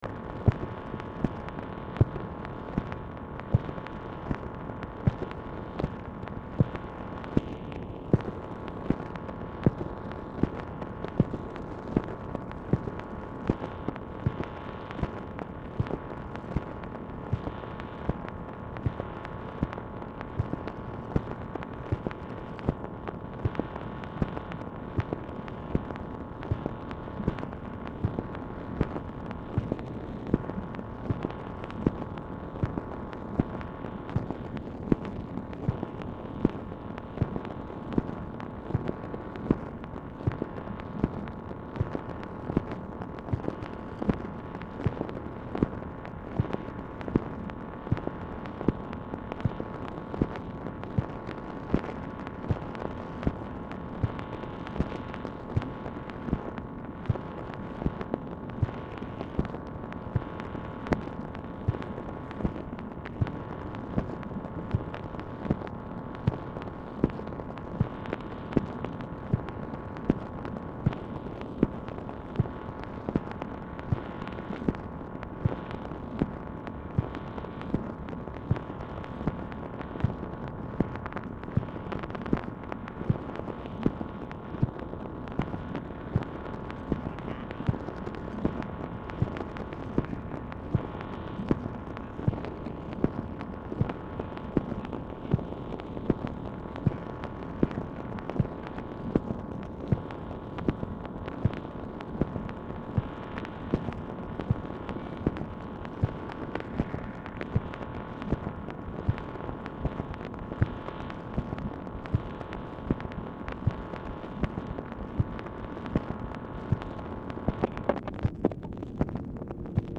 MACHINE NOISE
LBJ Ranch, near Stonewall, Texas
Telephone conversation
Dictation belt